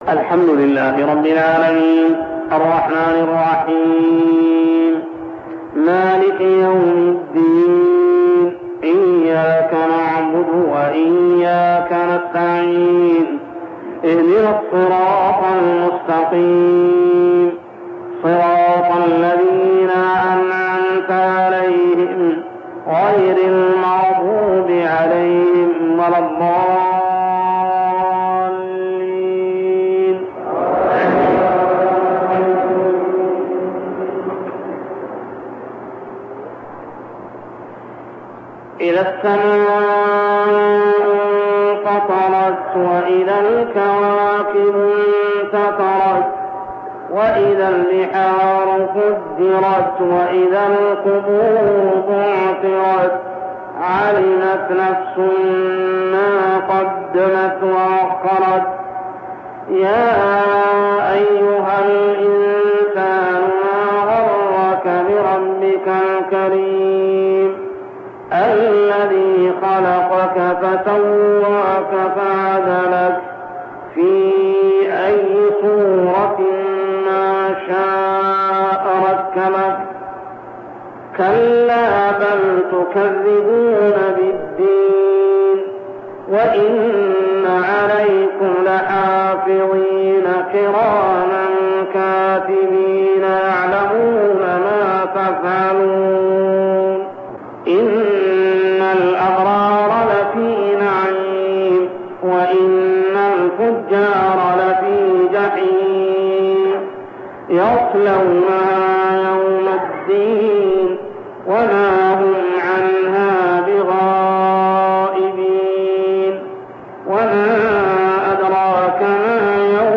تلاوة من صلاة المغرب لسورة الإنفطار كاملة عام 1402هـ | Maghrib prayer Surah AL-infitar > 1402 🕋 > الفروض - تلاوات الحرمين